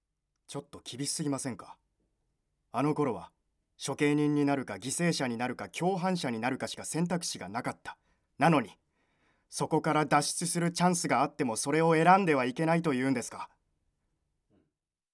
ボイスサンプル
セリフA